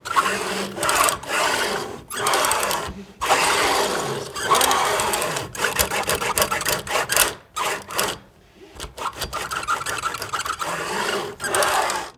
household
Curtain Noise